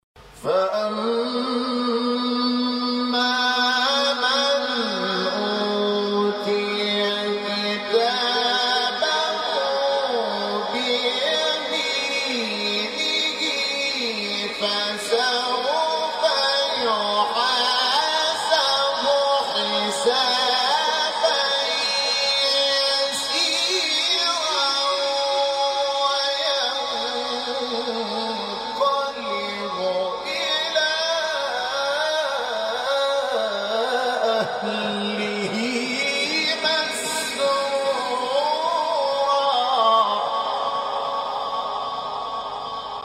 مقطع جدید 7-9 تلاوت انشقاق محمود شحات | نغمات قرآن
سوره:‌ انشقاق آیه:‌ 7-9 استاد:‌ محمود شحات مقام: بیات فَأَمَّا مَنْ أُوتِيَ كِتَابَهُ بِيَمِينِهِ ﴿٧﴾ فَسَوْفَ يُحَاسَبُ حِسَابًا يَسِيرًا ﴿٨﴾ وَيَنقَلِبُ إِلَىٰ أَهْلِهِ مَسْرُورًا ﴿٩﴾ قبلی